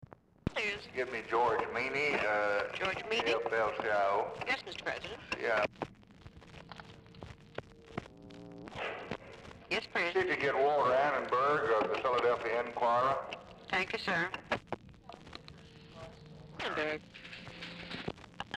Telephone conversation # 5519, sound recording, LBJ and TELEPHONE OPERATOR, 9/8/1964, time unknown | Discover LBJ
Format Dictation belt
Location Of Speaker 1 Oval Office or unknown location